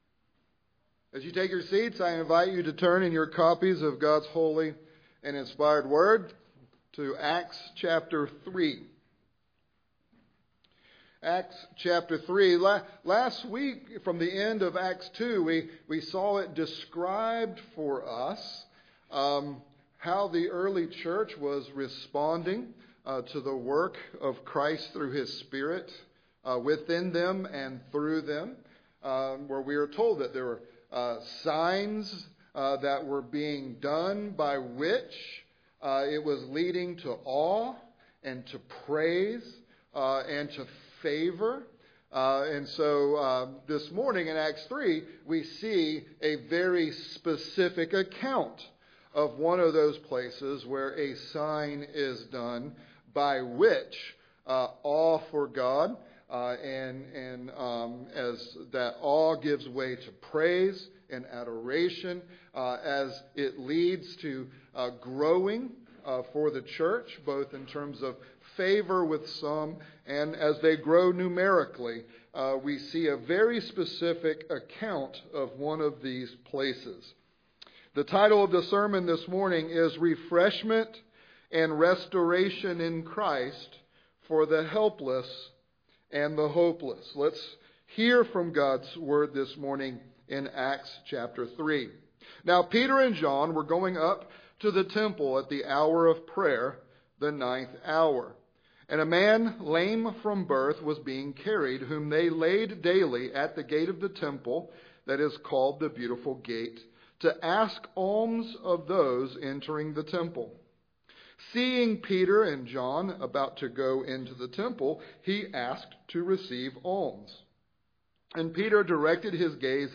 Sermons from Grace Covenant Church: Dallas, GA